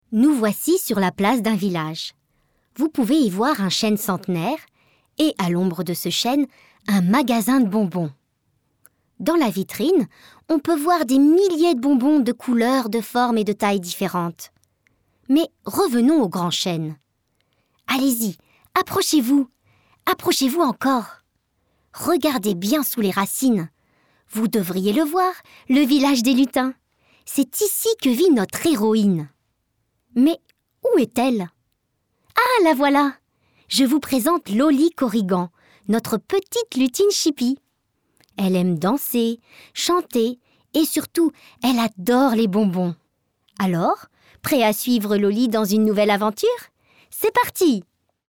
Audiobook
J'ai une voix jeune, légère, cristalline et pétillante...